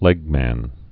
(lĕgmăn, -mən)